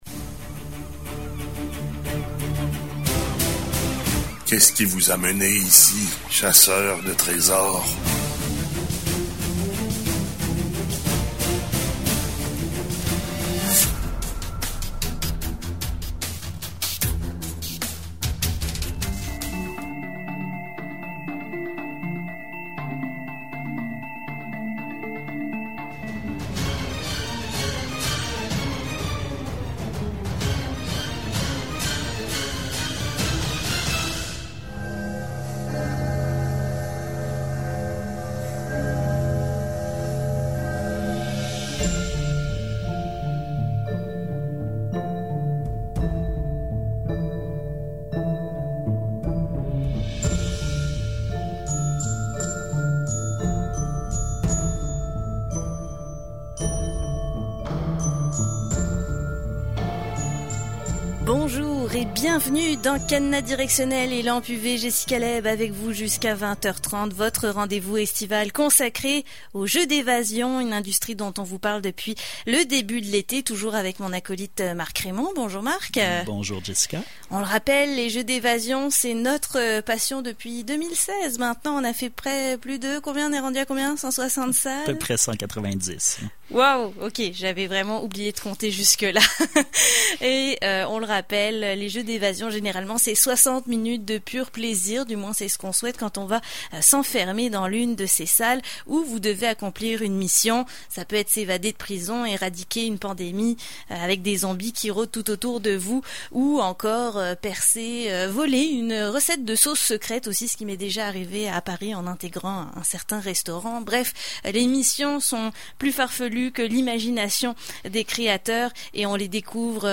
🎙 Avec nous, en studio :
📞 Et avec nous, via la magiiiiiie du téléphone :